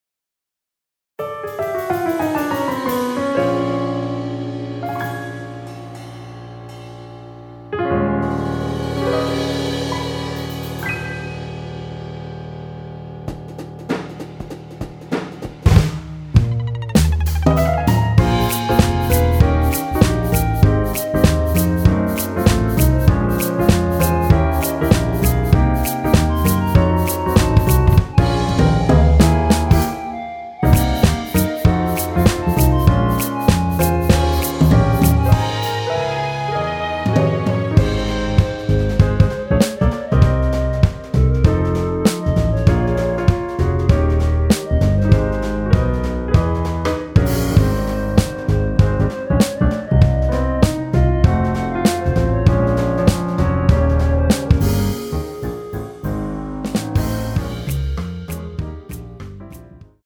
원키에서(-3)내린 멜로디 포함된 MR입니다.(미리듣기 확인)
Ab
멜로디 MR이라고 합니다.
앞부분30초, 뒷부분30초씩 편집해서 올려 드리고 있습니다.
중간에 음이 끈어지고 다시 나오는 이유는